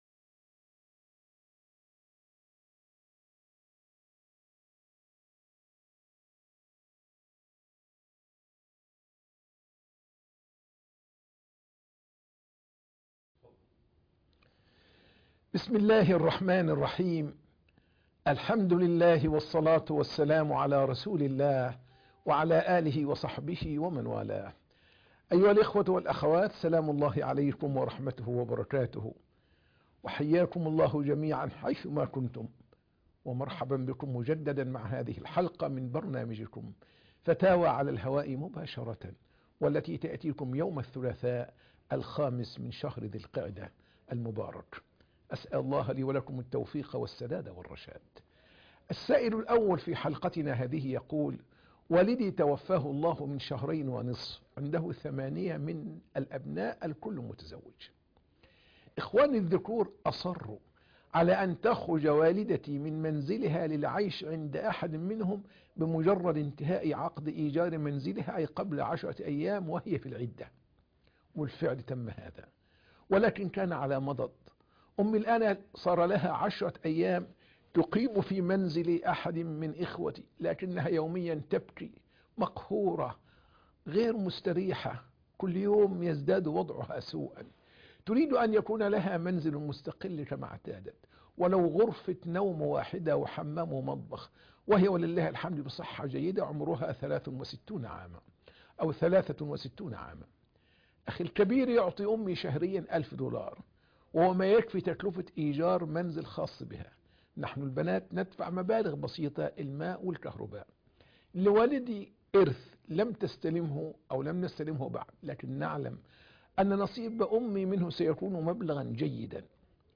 فتاوى على الهواء